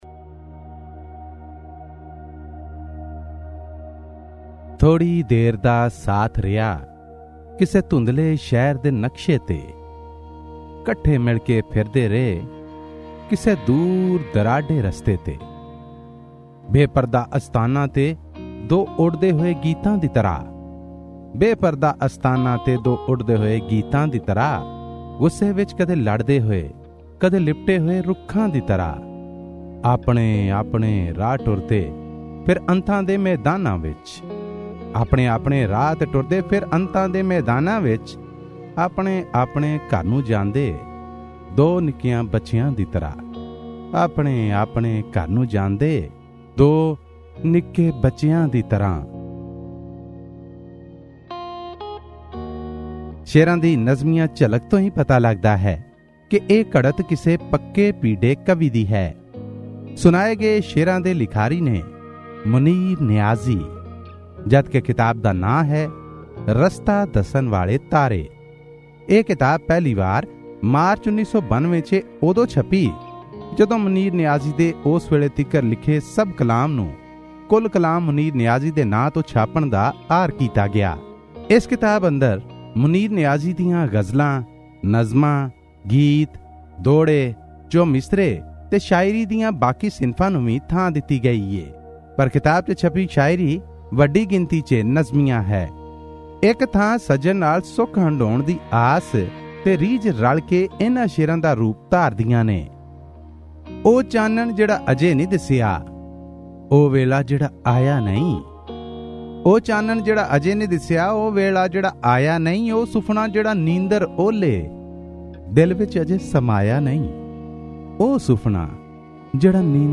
Pakistani Punjabi poetry book review: 'Rasta Dasan Walay Taaray' by Munir Niazi